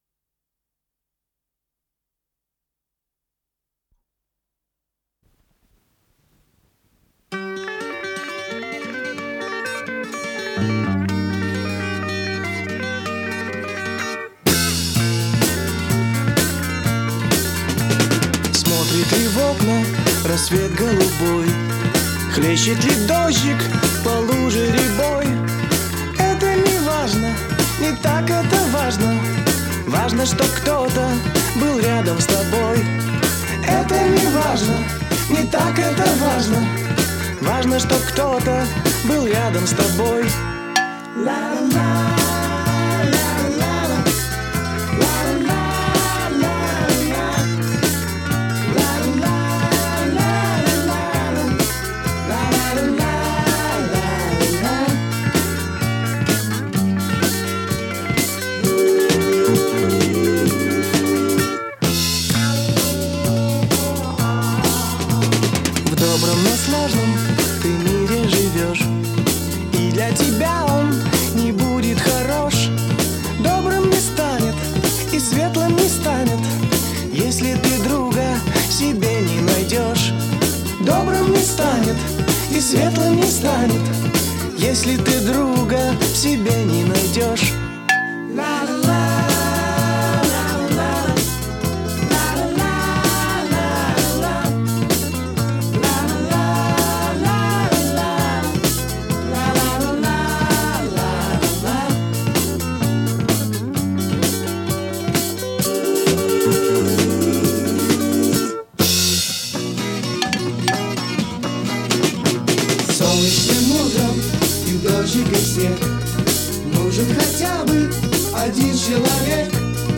с профессиональной магнитной ленты
песня
ВариантДубль моно